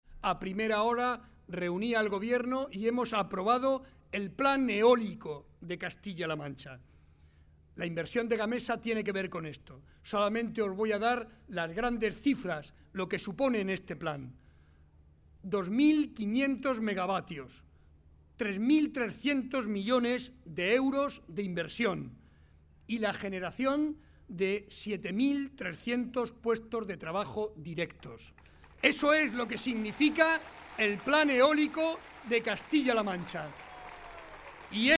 Ante 2.000 personas, en el Paseo del Prado de Talavera de la Reina, junto al alcalde y candidato a la reelección, José Francisco Rivas, Barreda se limitó a explicar a los asistentes su proyecto para Castilla-La Mancha basado en compromisos concretos como el anunciado, en una forma de gobernar desde la cercanía y en la dedicación exclusiva a los castellano-manchegos. Además de energías limpias y renovables, el presidente Barreda habló de agua, de Educación, Sanidad y servicios sociales.